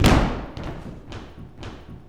115 STOMP2-L.wav